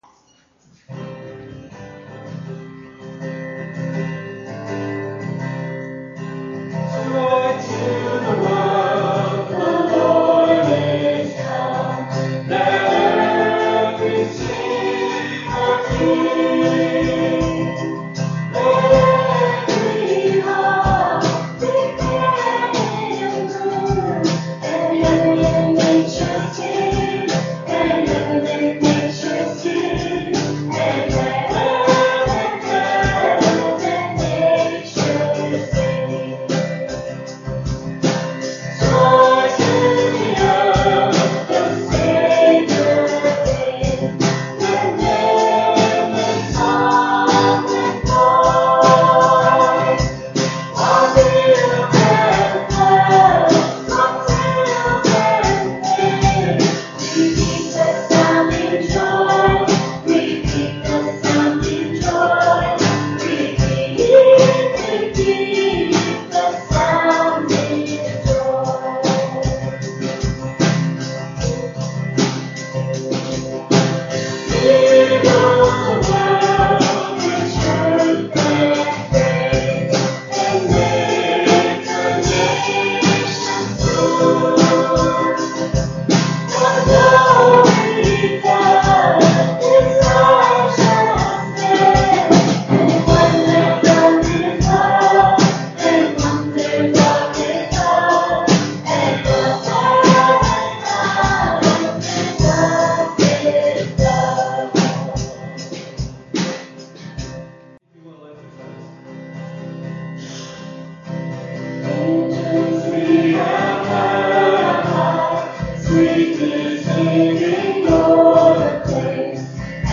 vocals and guitar
drums.